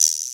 Vibraslap 909.wav